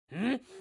描述：Voz de personaje坟墓，没有领带palabras，独唱儿子sonidos de rabia。